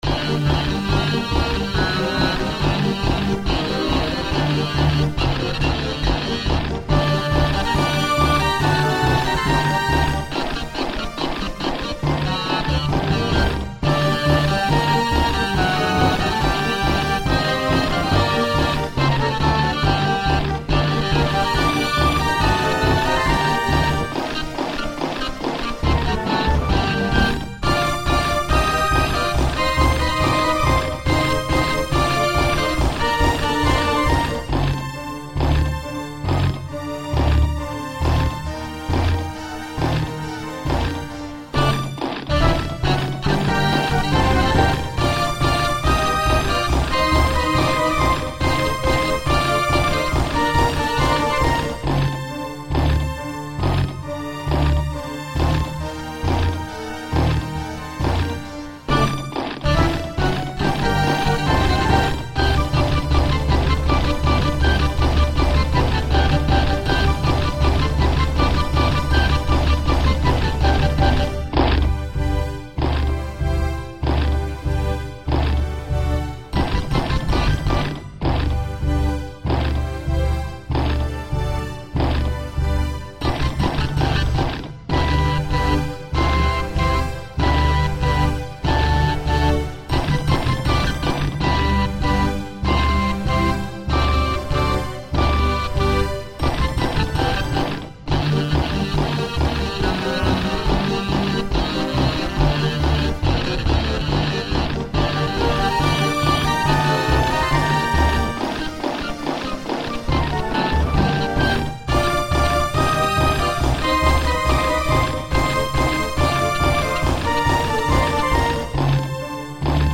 Hungarian March